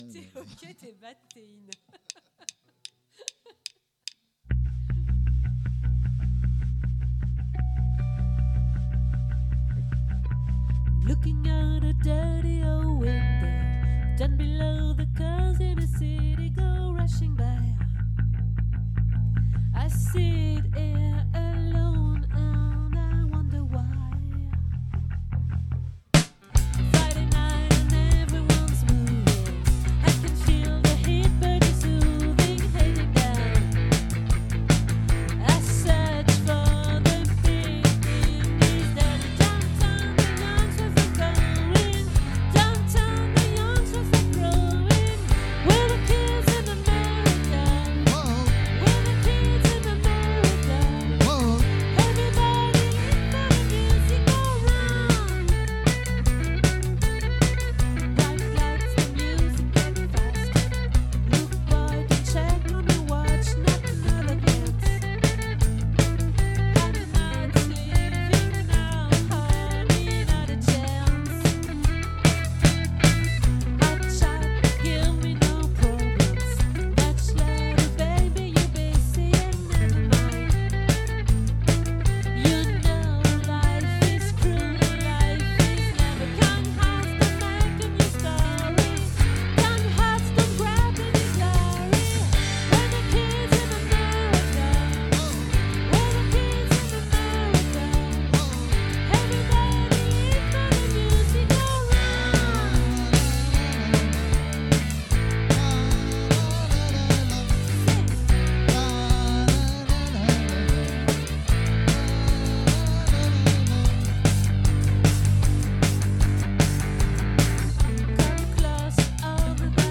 🏠 Accueil Repetitions Records_2025_01_06